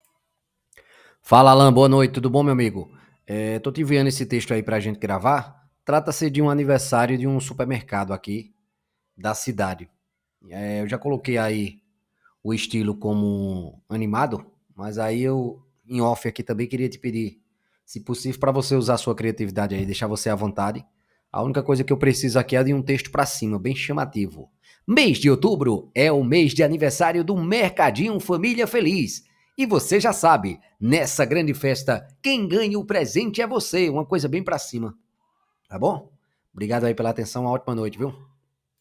AMIGO PODE FAZER MAIS CALMA, FICOU MUITO RAPIDO E OFEGANTE !
OUTRA OBSERVAÇÃO É REFERENTE A QUALIDADE DO AUDIO, NÃO ESTÁ LEGAL E COM MUITO PUFF DURANTE NA GRAVAÇÃO